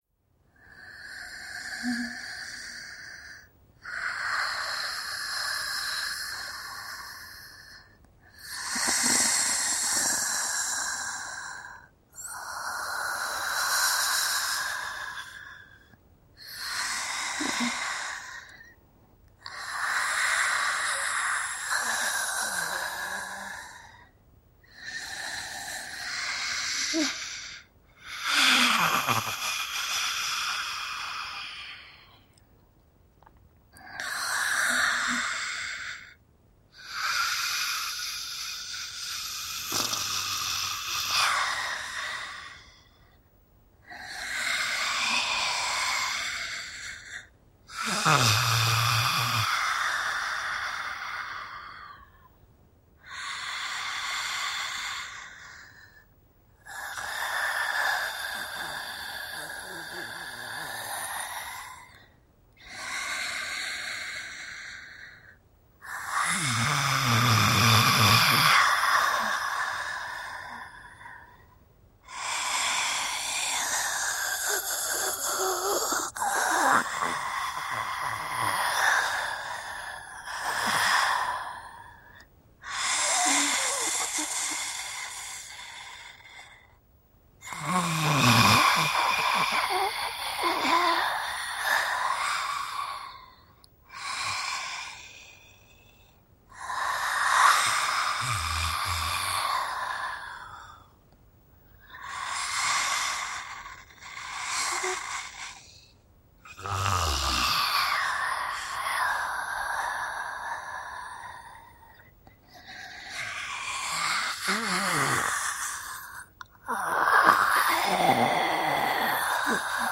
Здесь собраны уникальные аудиозаписи, воссоздающие атмосферу древних гробниц: от приглушенных стонов до зловещего шелеста бинтов.
Шепот дыхания зомби